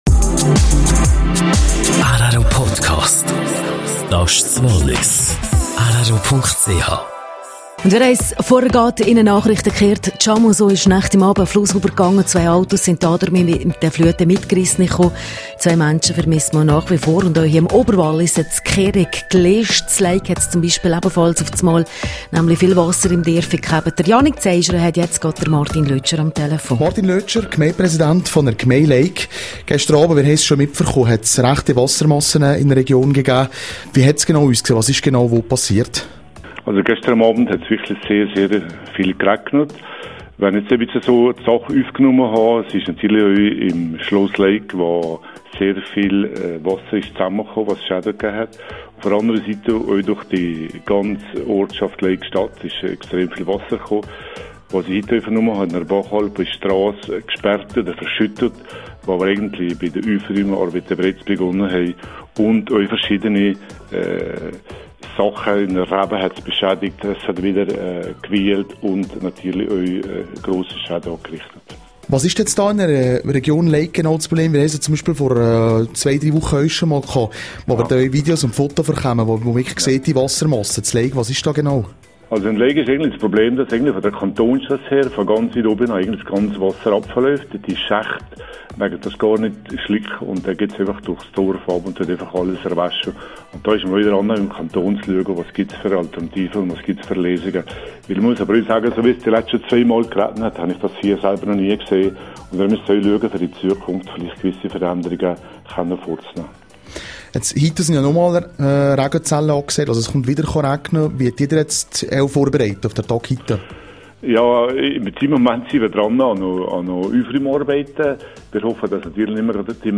Für Montag sind weitere starke Regenfälle für die Region gemeldet./sr Starker Regen im Oberwallis: Interview mit Gemeindepräsident von Leuk, Martin Lötscher.